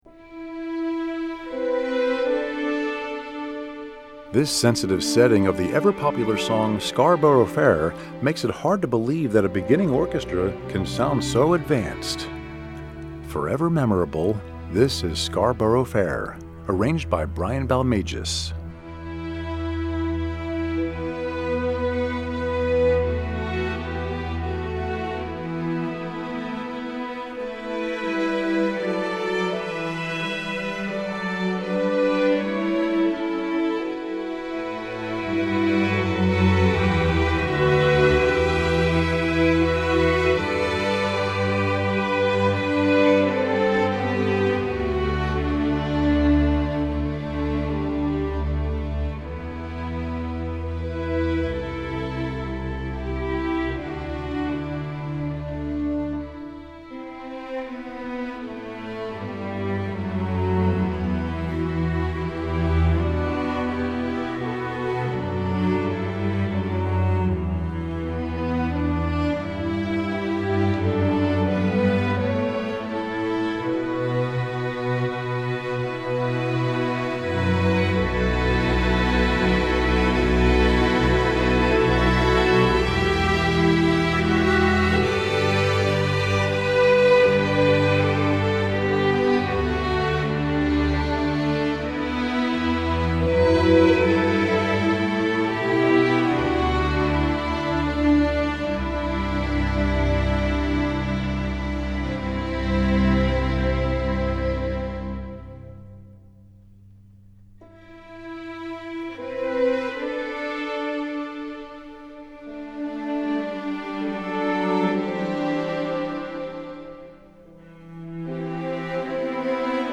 Composer: English Folk Song
Voicing: String Orchestra